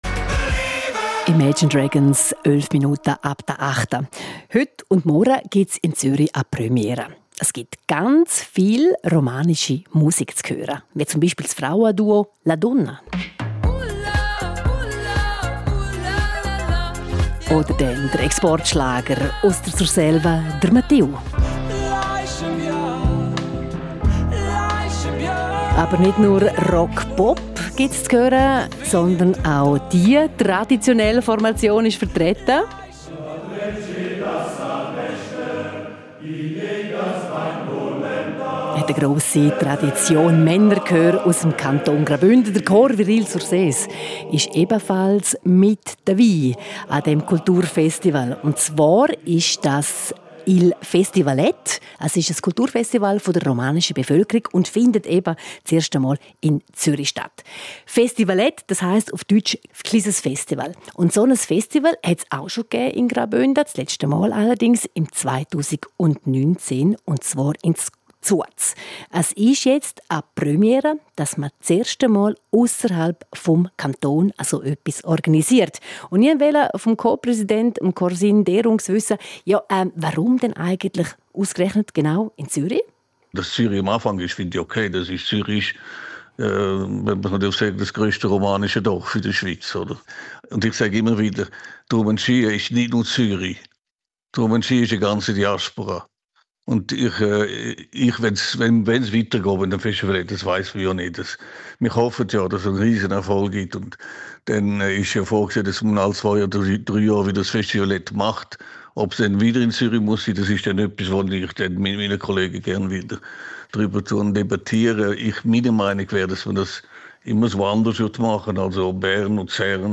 Radiointerview Teil 1 vom 19. Juni 2025 im Tagesprogramm